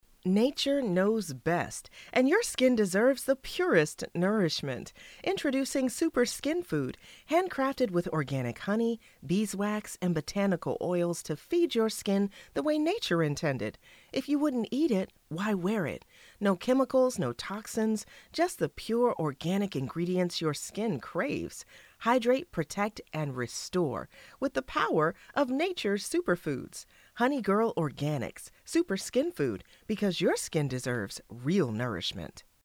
General American